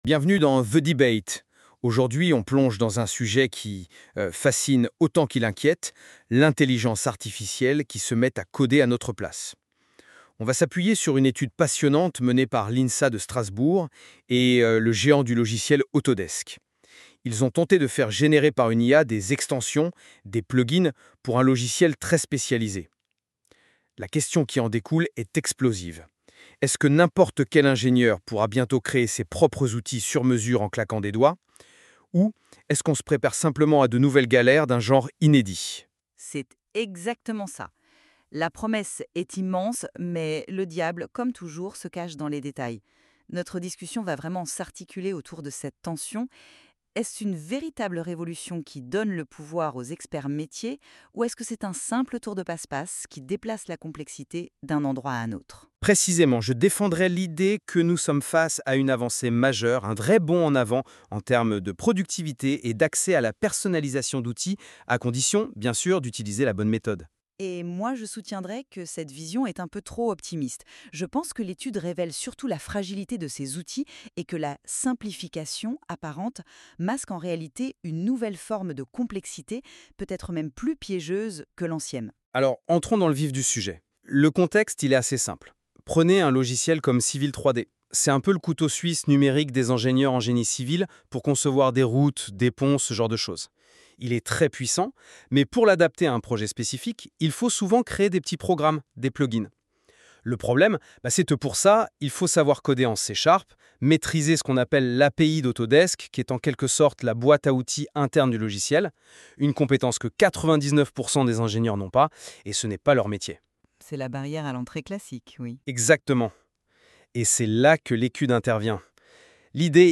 [!Success] Débat contradictoire /uploads/default/original/2X/d/d1ed12c9ccd6c1cb71b40ad9edb96174f052a196.mp3